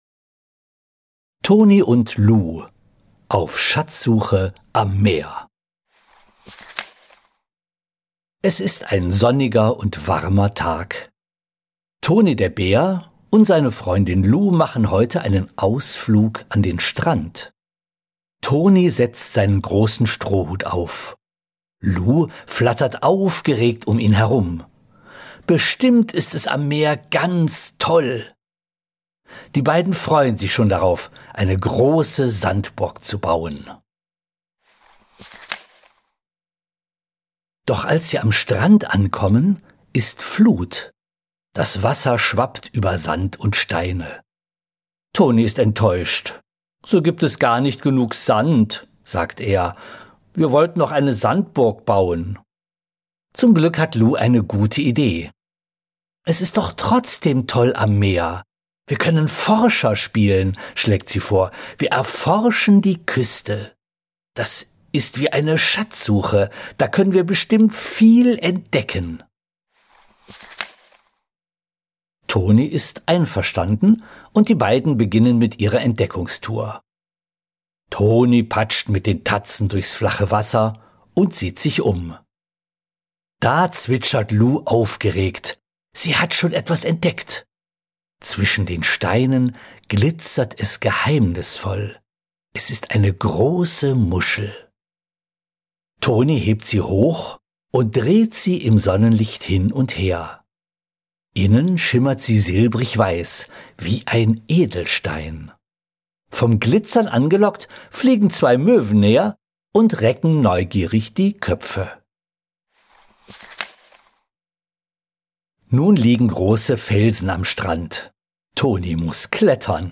Jetzt gibt es ausgewählte Kinderbücher auch als kostenlose Hörversionen zum Download.